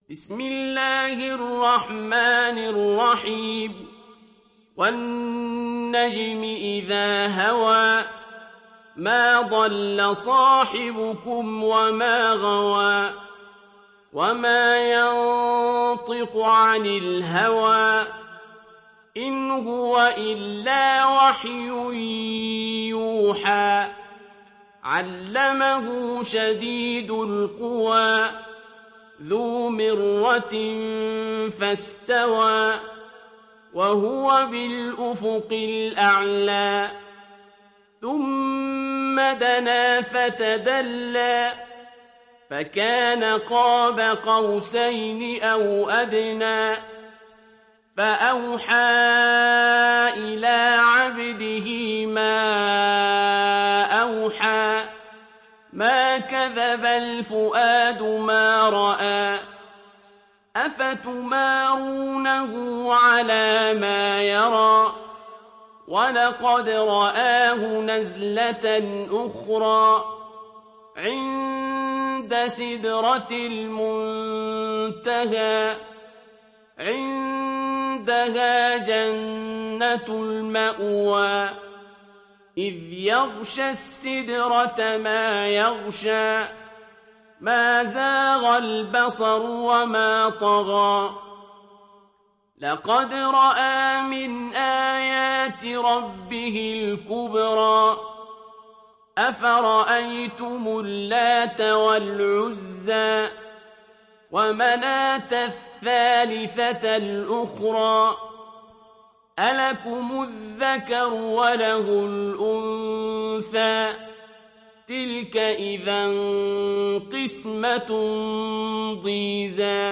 تحميل سورة النجم mp3 بصوت عبد الباسط عبد الصمد برواية حفص عن عاصم, تحميل استماع القرآن الكريم على الجوال mp3 كاملا بروابط مباشرة وسريعة